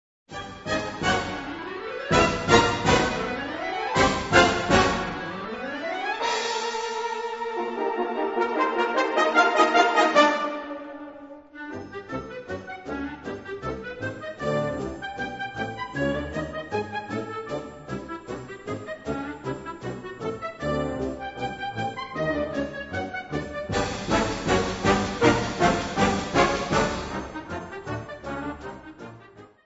Categorie Harmonie/Fanfare/Brass-orkest
Subcategorie Ouverture, bewerkt
Bezetting Ha (harmonieorkest)